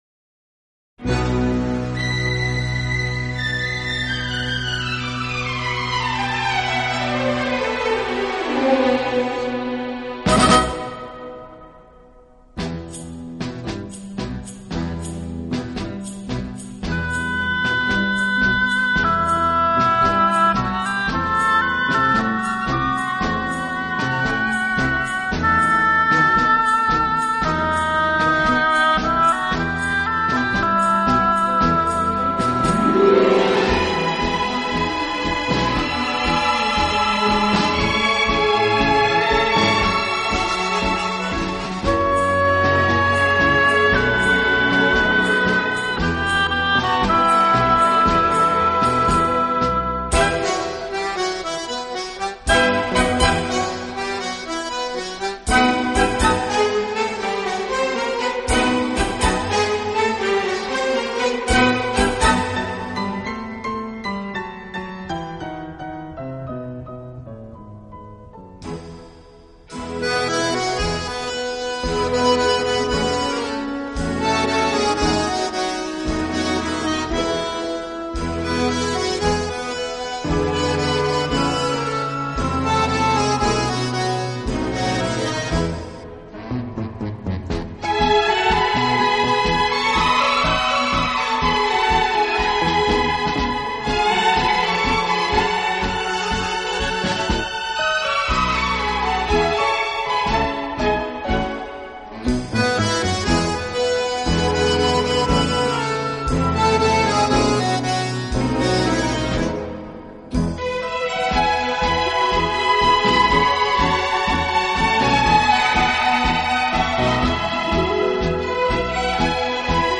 乐背景的不同，以各种乐器恰到好处的组合，达到既大气有力又尽显浪漫的效果。